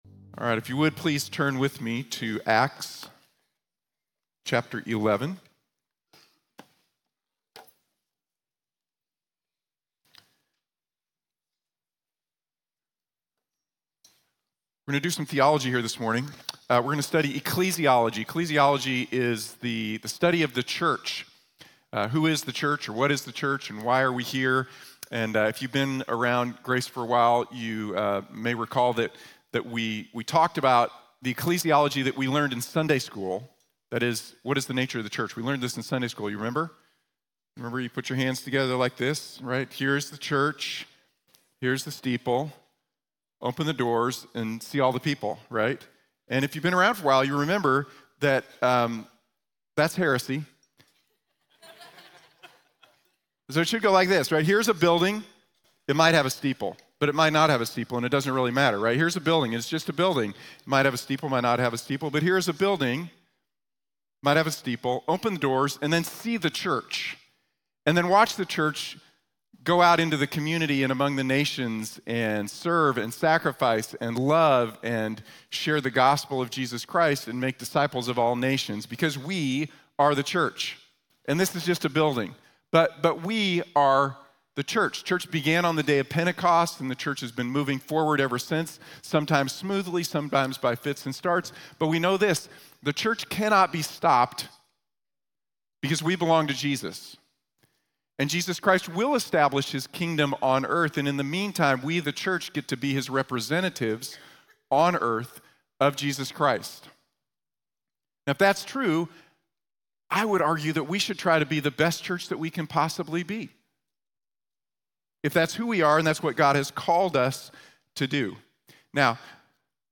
We are the Church | Sermon | Grace Bible Church